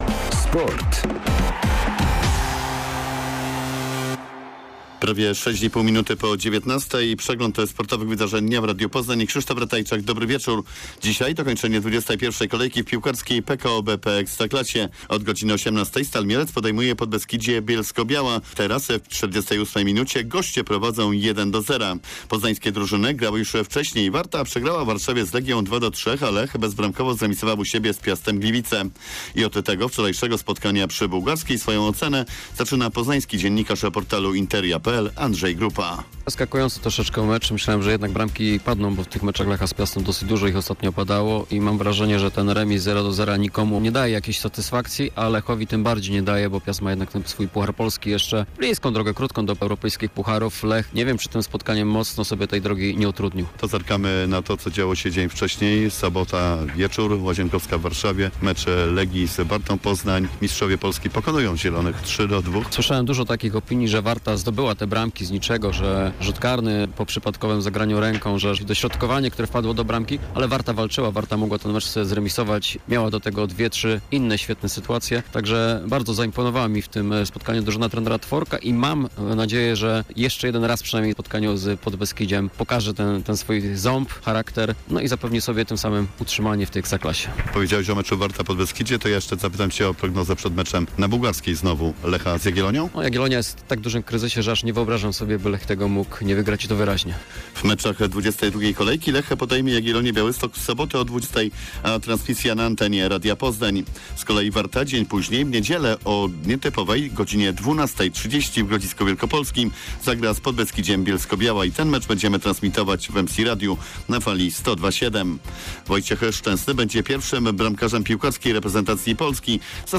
15.03.2021 SERWIS SPORTOWY GODZ. 19:05